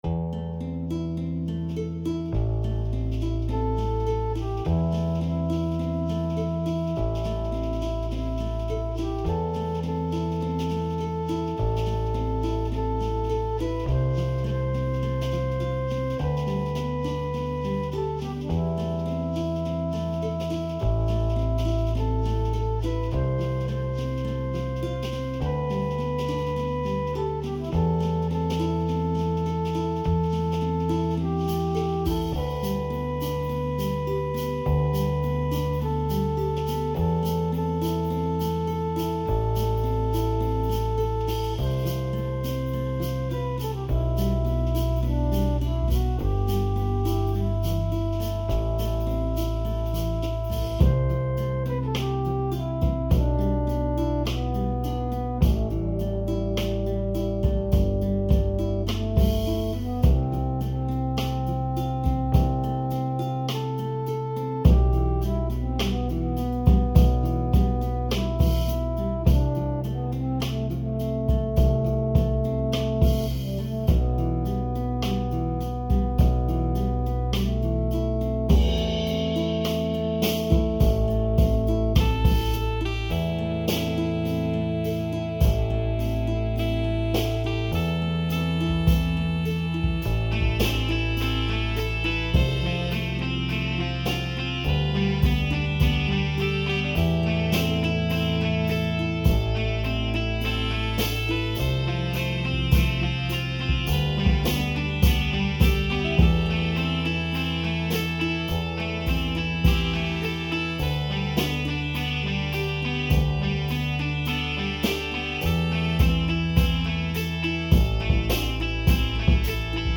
an irish folk song
drums, perc, ac-guitar, e-bass, keyboard
recorded with Lexicon Omega and Cubase5